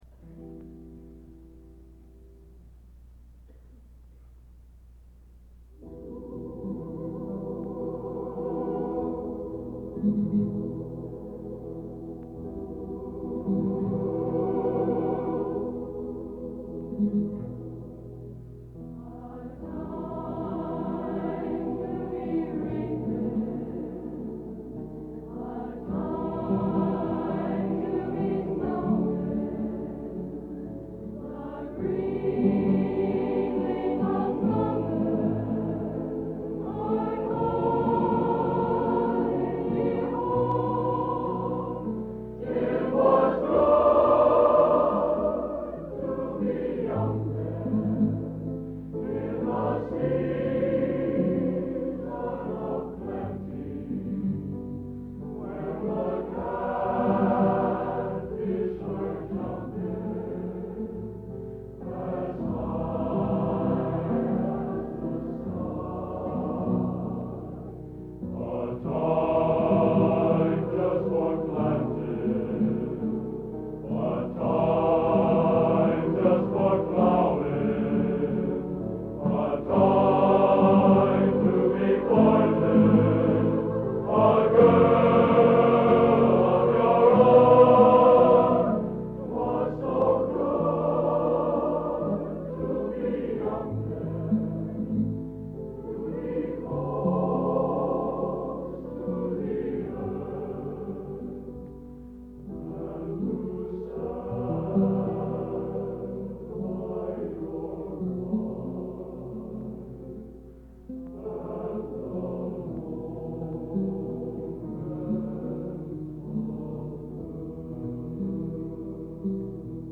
Genre: Popular / Standards | Type: